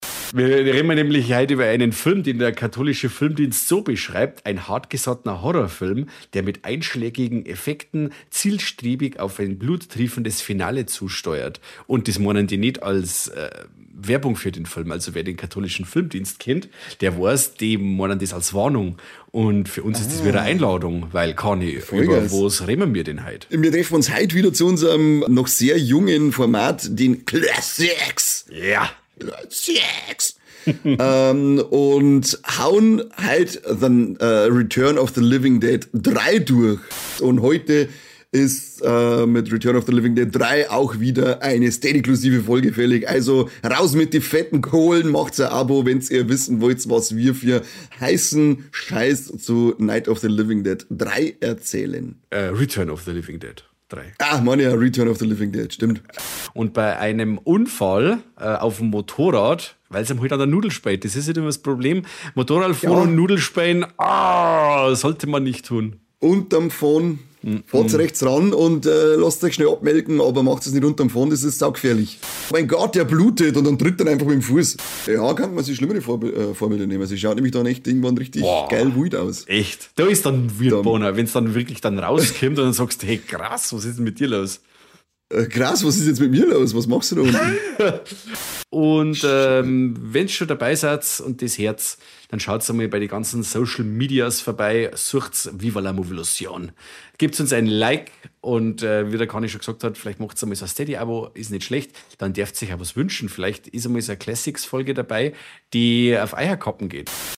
Wie bei einer Stammtischrunde gehts in so mancher Sendung mit bayrischem Dialekt zu.